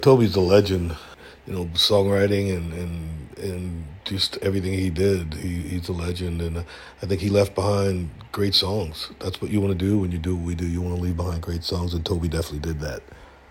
Darius Rucker talks about what he feels Toby Keith left behind -- great songs.